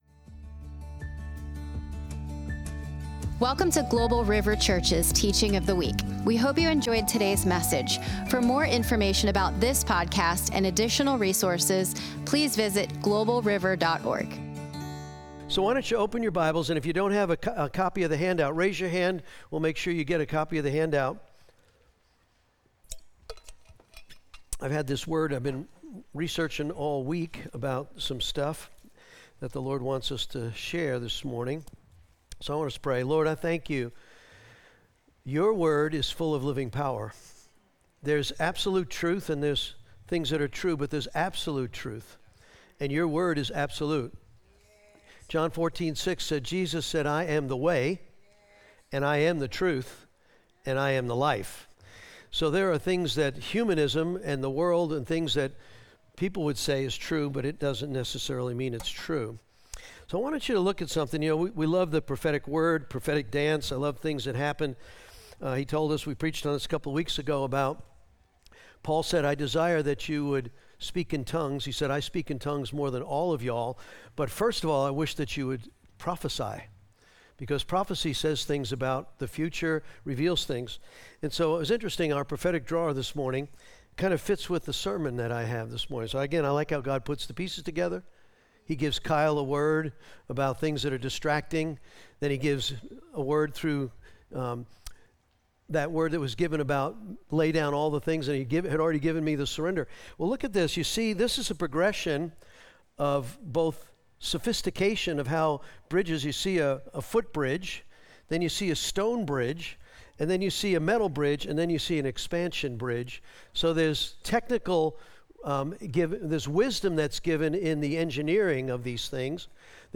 Now Learn The Lesson From The Fig Tree - Sunday Morning Global River Message Of The Week podcast To give you the best possible experience, this site uses cookies.